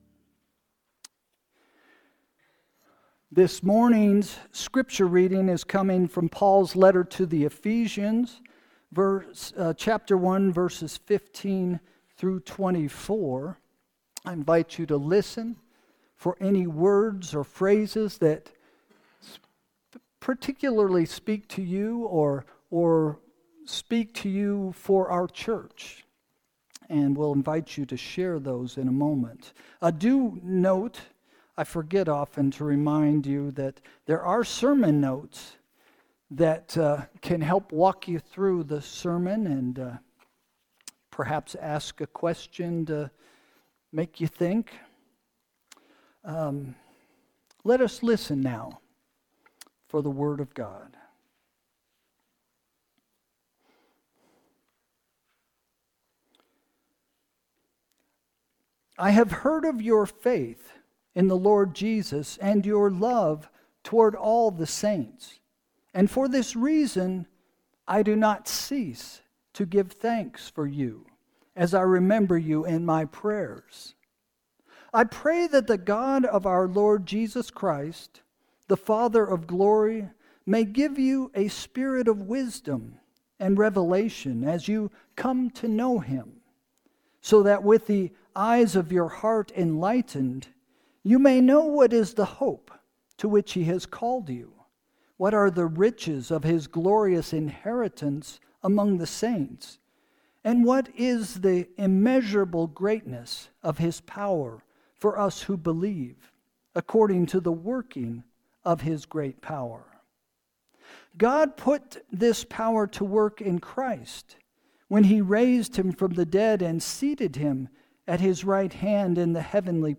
Sermon – January 5, 2025 – “Paul’s Prayer for 2025”